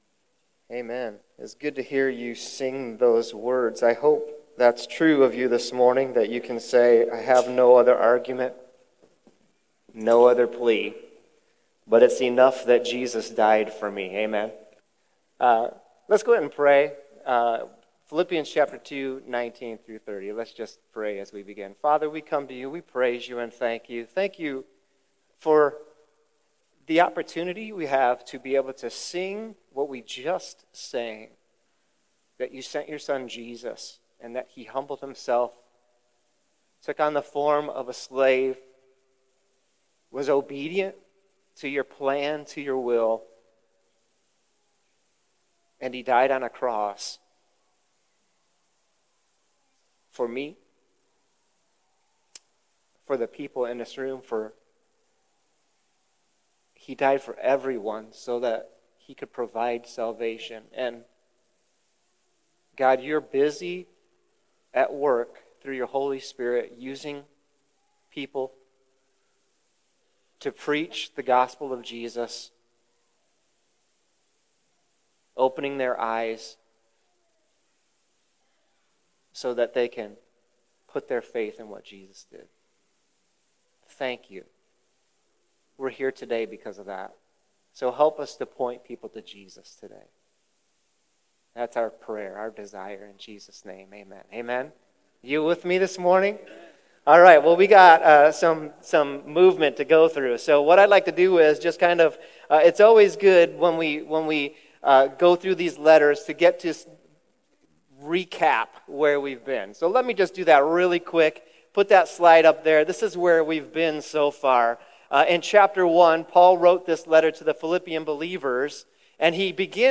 Sermon Questions Read Philippians 2:19-30 Look at Paul's list of commendations for Timothy.